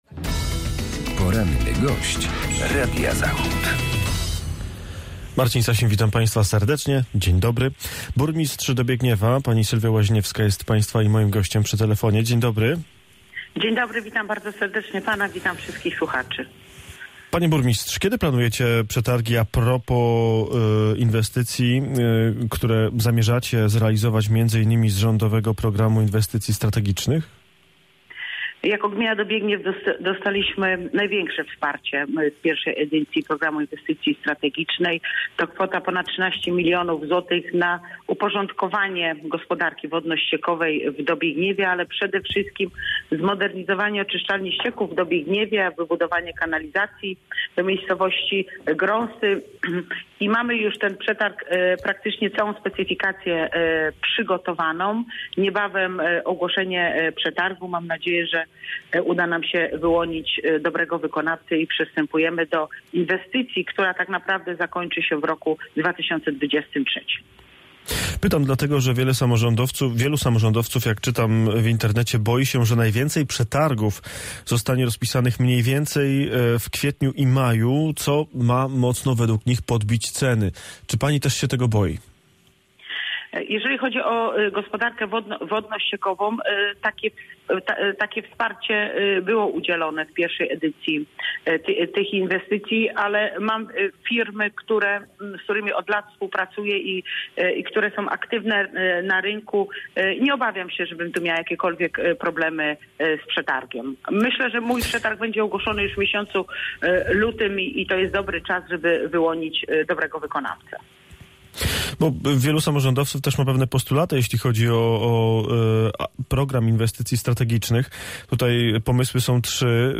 Sylwia Łaźniewska, burmistrz Dobiegniewa